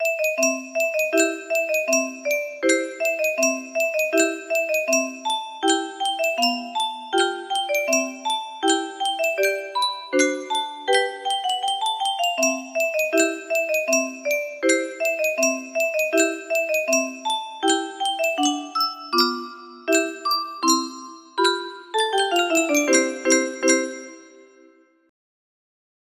Radetzky Cajita palmas music box melody
Yay! It looks like this melody can be played offline on a 30 note paper strip music box!
BPM 160